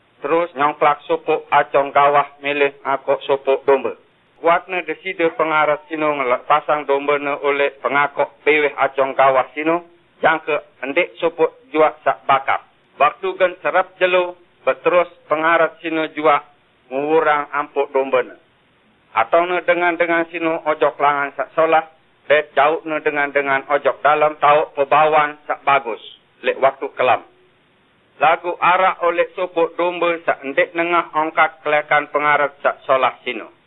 13 February 2017 at 4:48 am Five vowel system, apparent vowel harmony. Not many unusual phonemes, but there are /t͡s/ and /ⁿd/.. Few to no consonant clusters, and the only final consonants I heard are nasals and /s/.